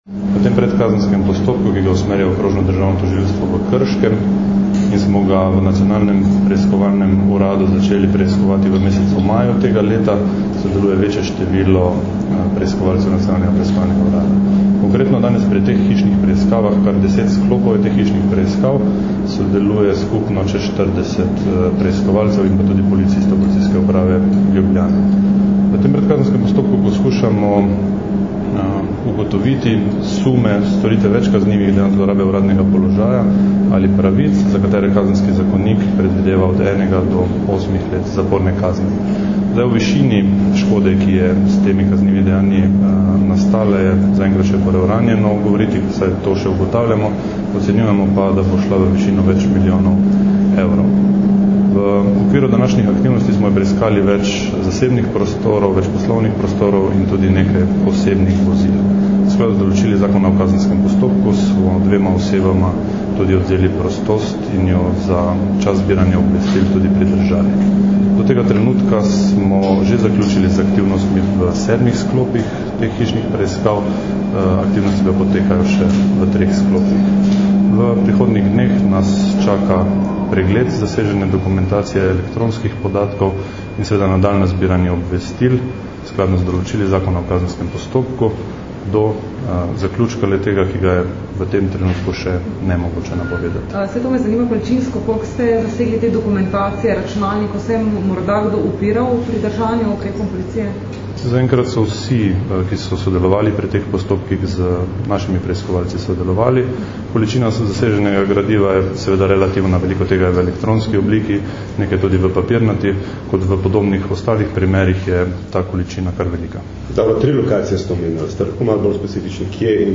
Policija na območju Novega mesta, Ljubljane in Kranja opravlja več hišnih preiskav - informacija z izjave za javnost